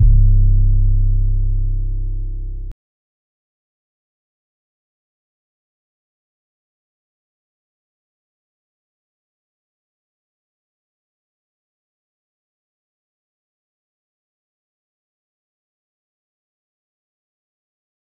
huncho 808.wav